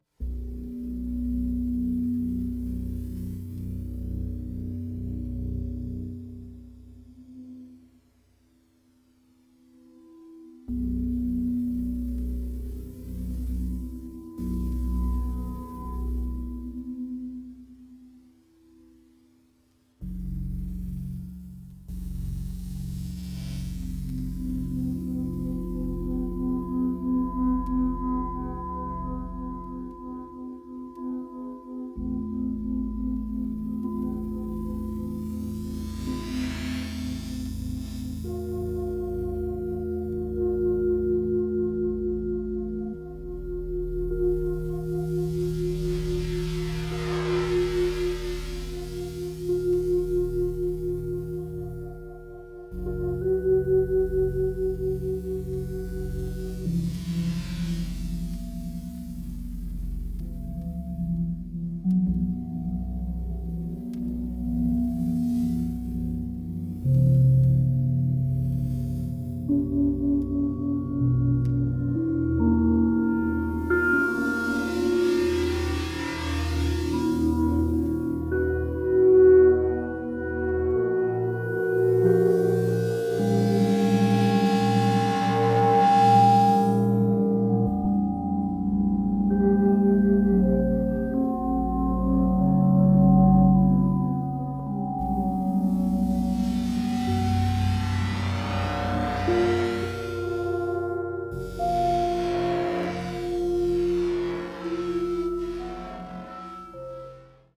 The atonality of the score is profound